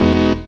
HOUSE 4-R.wav